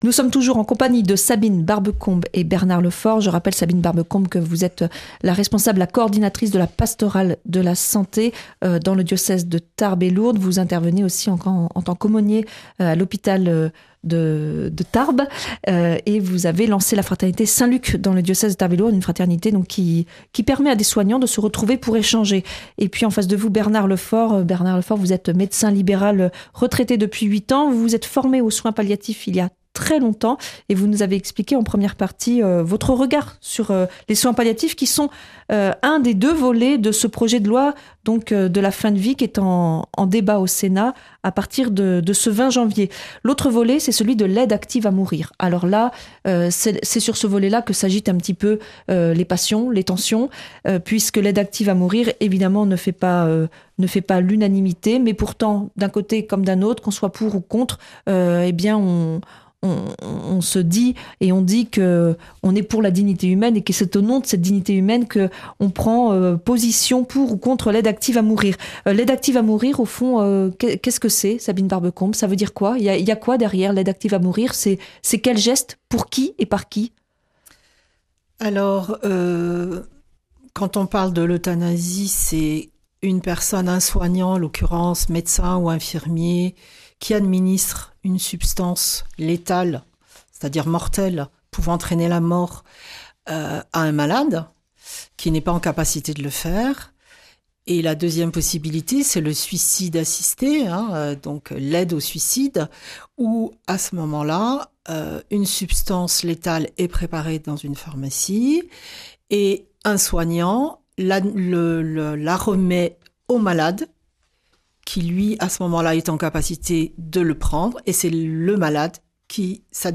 à l'occasion de l'inauguration de l'école ND et du presbytère d'Argeles-Gazost. Un evenement après l'incendie devastateur de 2022.